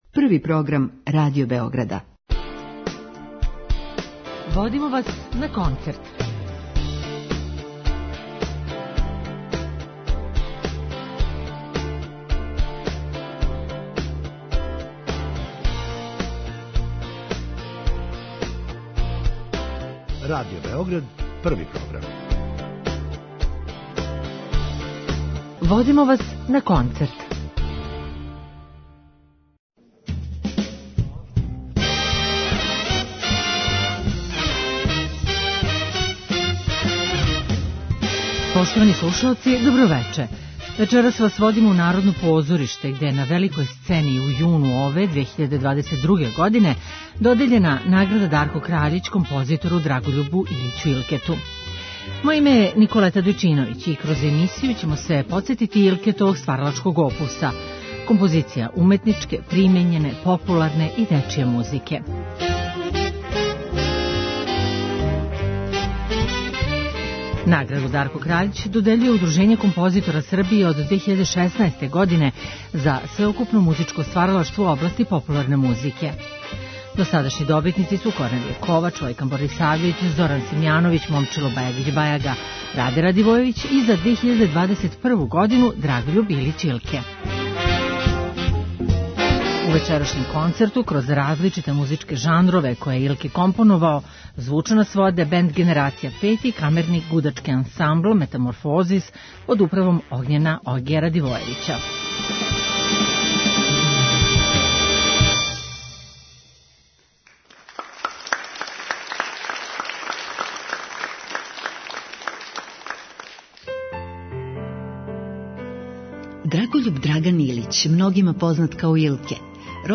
на Великој сцени Народног позоришта
камерни гудачки ансамбл